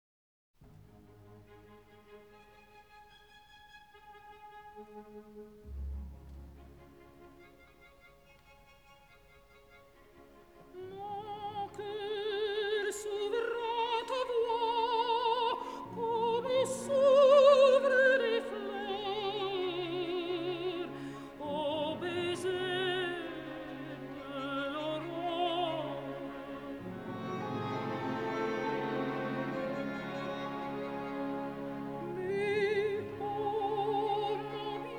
Classical Opera
Жанр: Классика